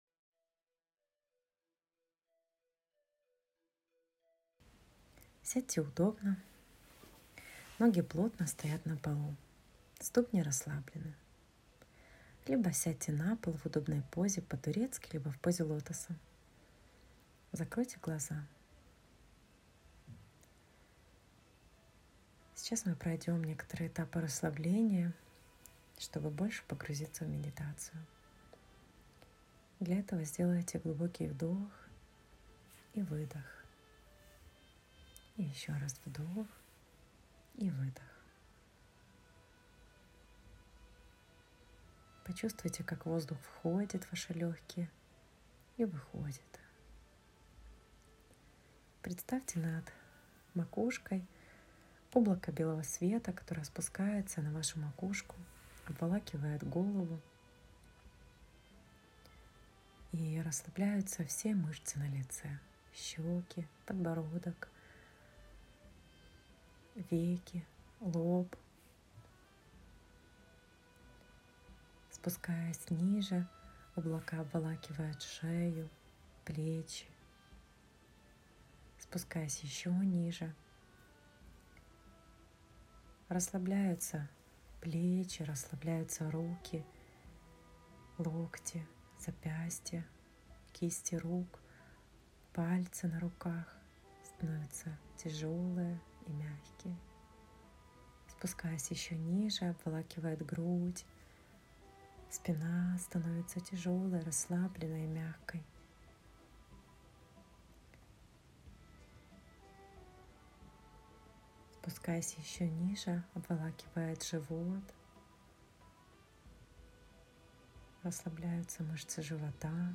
5. Медитація «Опора»